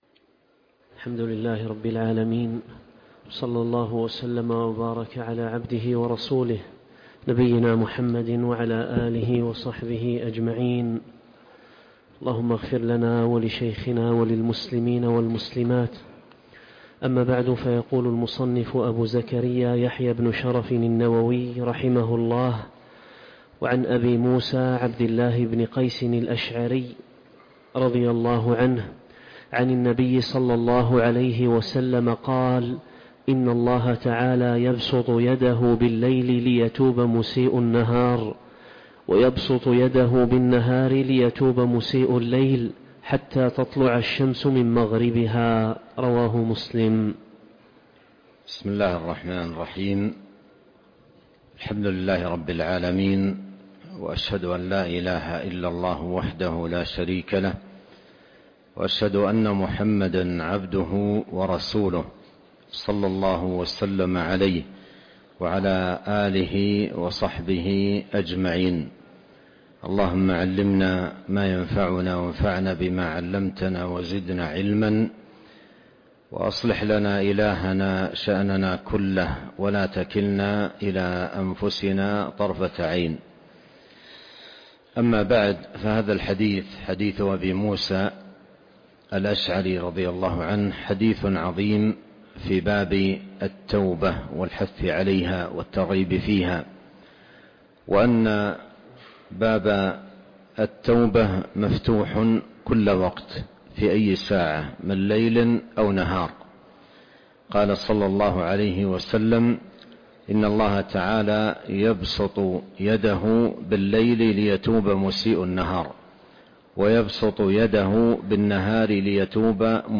شرح رياض الصالحين الدرس 8